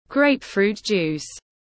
Nước bưởi ép tiếng anh gọi là grapefruit juice, phiên âm tiếng anh đọc là /ˈɡreɪp.fruːt ˌdʒuːs/